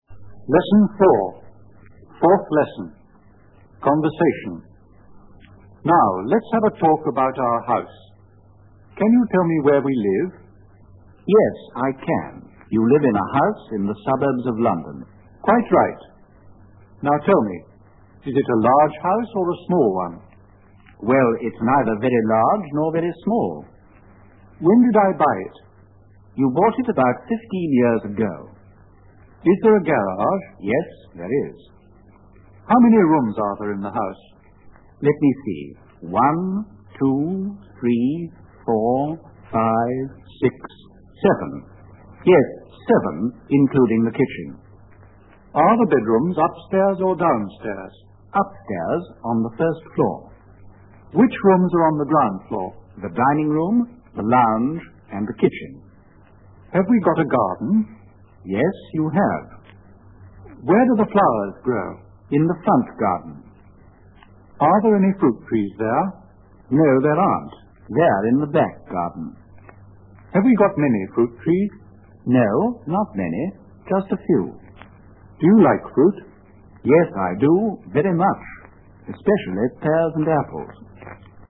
04 Our house (Conversation)